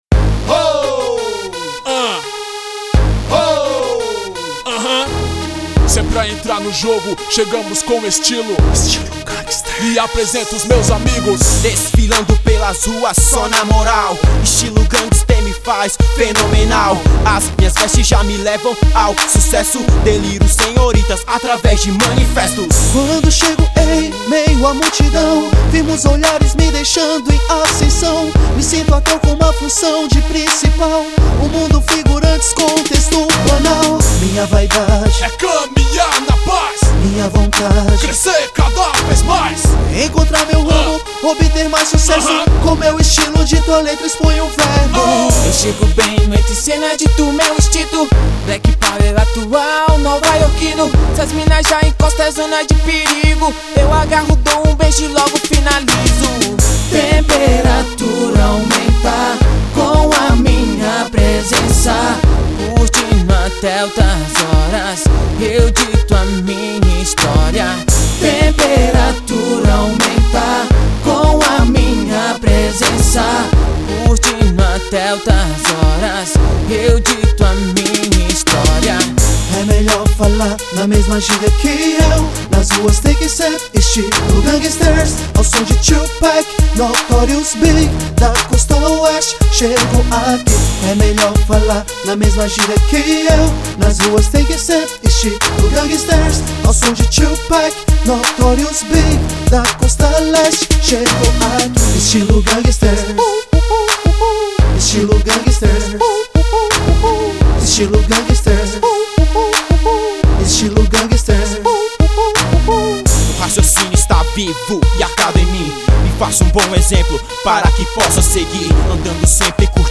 EstiloR&B